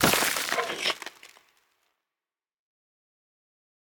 Minecraft Version Minecraft Version 25w18a Latest Release | Latest Snapshot 25w18a / assets / minecraft / sounds / mob / stray / convert3.ogg Compare With Compare With Latest Release | Latest Snapshot